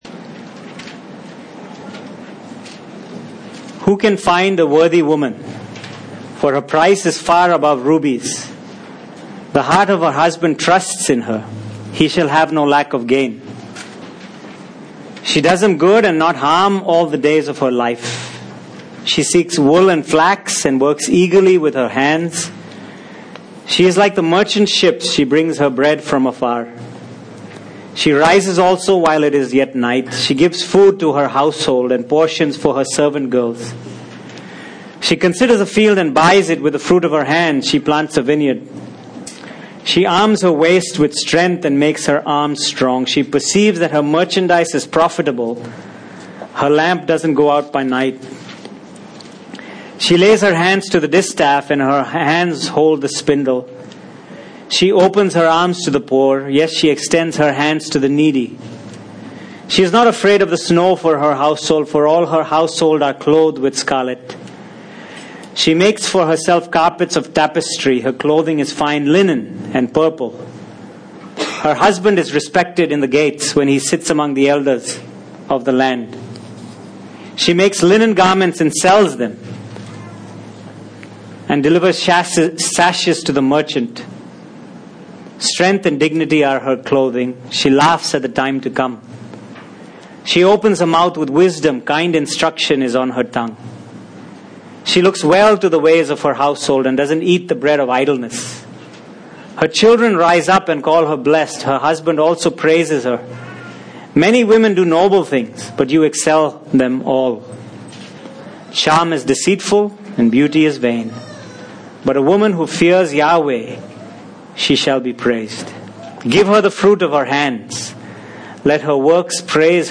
Passage: Proverbs 31:10-31 Service Type: Sunday Morning